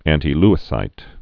(ăntē-lĭ-sīt, ăntī-)